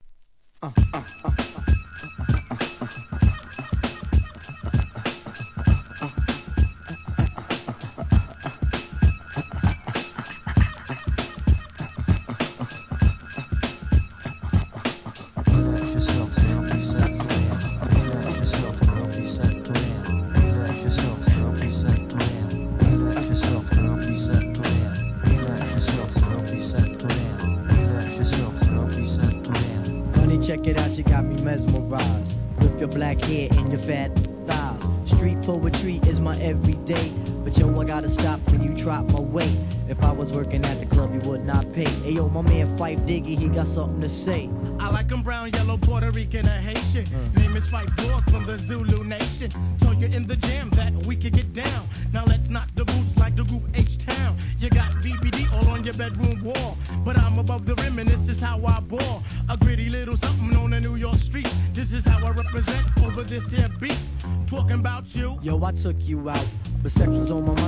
HIP HOP/R&B
これもメロウクラシック!!